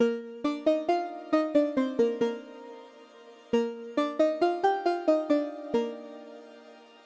醒来的合成器
Tag: 136 bpm Trap Loops Synth Loops 1.19 MB wav Key : Unknown